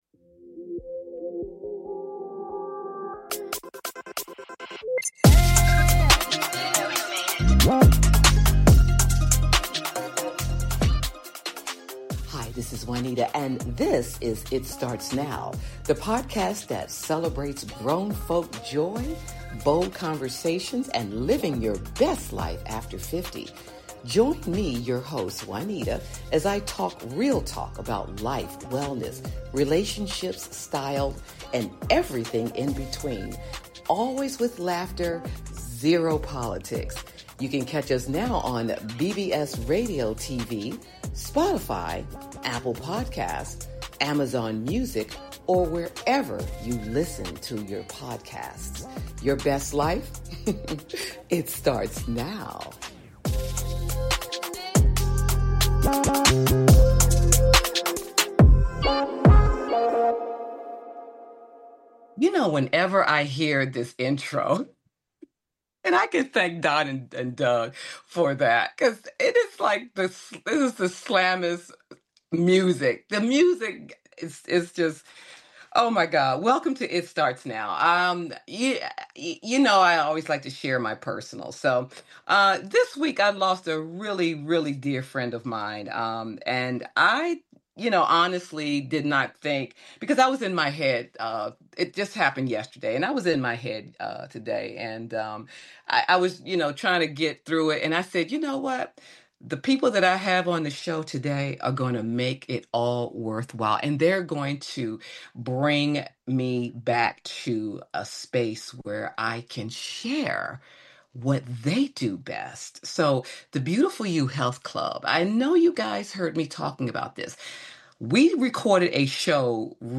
Podcast Bio : is a lively, conversational podcast that flips the script on aging.